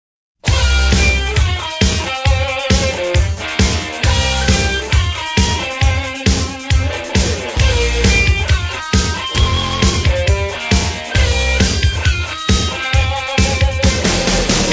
a guitar.